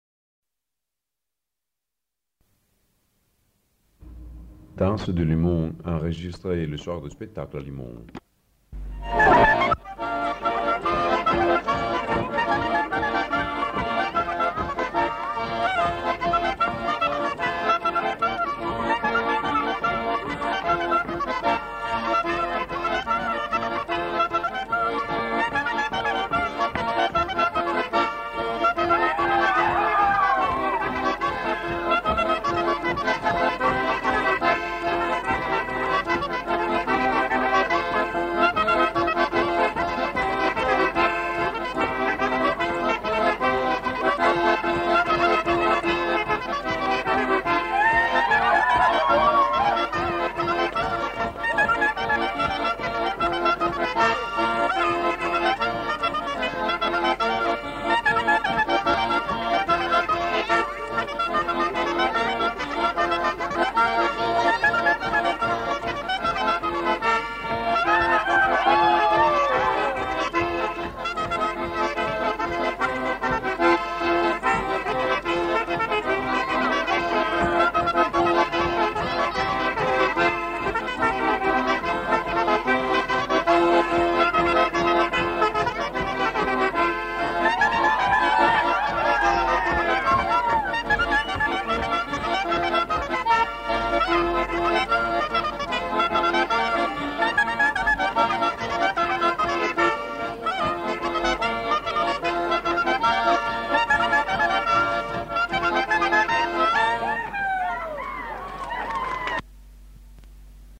Instrumental. Accordéon chromatique, clarinette.
Aire culturelle : Val Vermenagna
Lieu : Limone
Genre : morceau instrumental
Instrument de musique : clarinette ; accordéon chromatique
Notes consultables : Les deux musiciens ne sont pas identifiés.